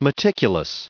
505_meticulous.ogg